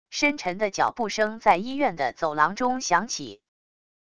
深沉的脚步声在医院的走廊中响起wav音频